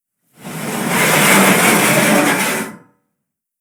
Unlock Ladder.wav